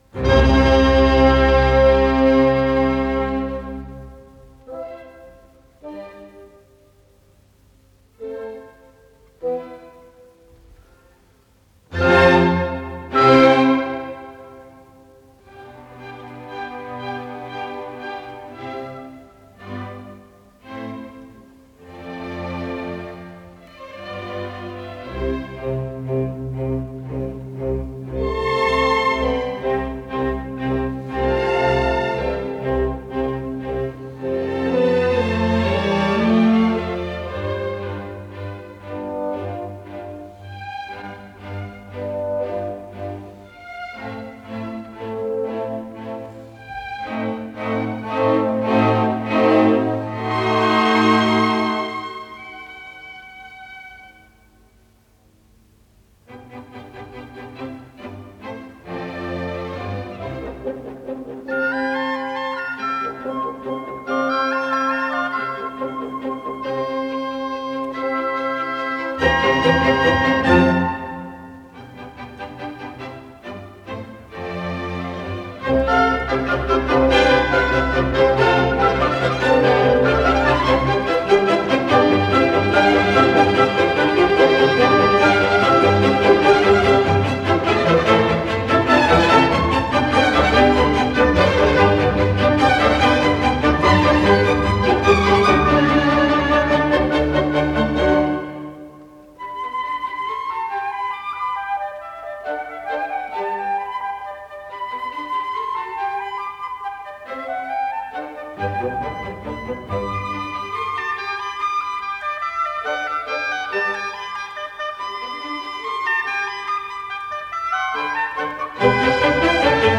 с профессиональной магнитной ленты
ИсполнителиБольшой симфонический оркестр Всесоюзного радио и Центрального телевидения
ВариантДубль моно